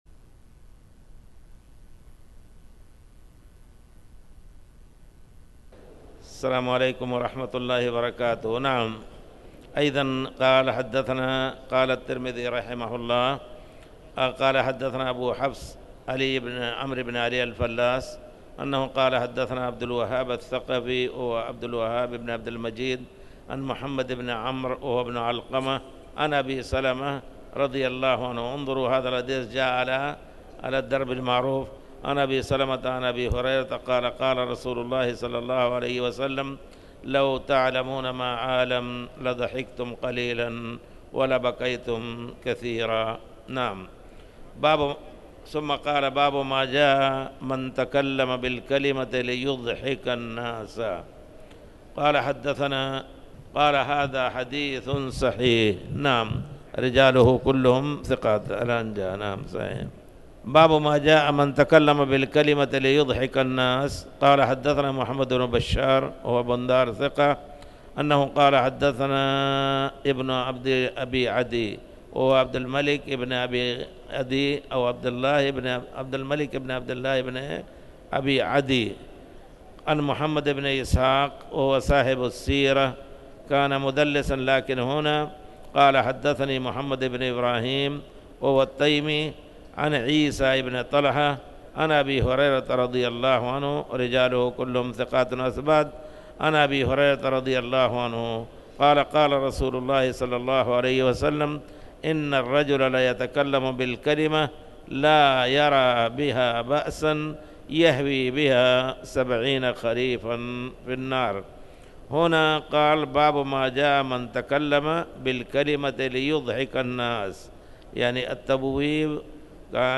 تاريخ النشر ٣ جمادى الأولى ١٤٣٩ هـ المكان: المسجد الحرام الشيخ